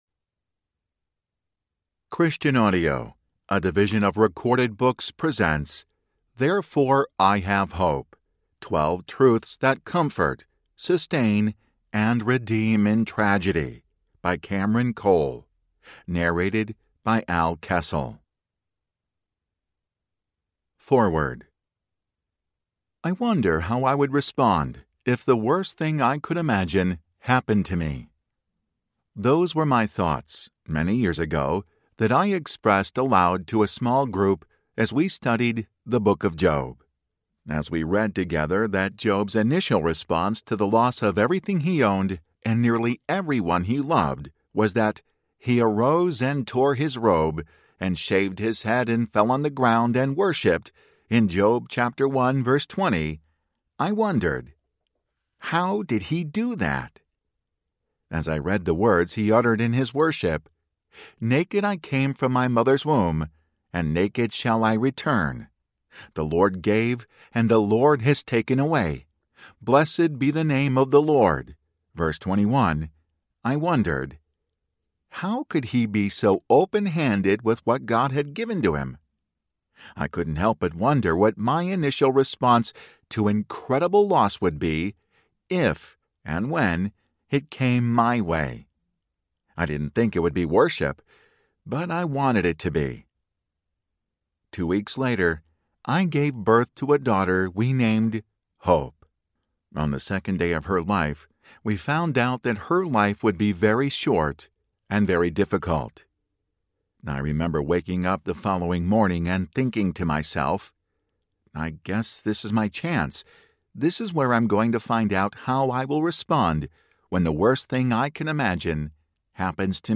Therefore I Have Hope Audiobook
5.6 Hrs. – Unabridged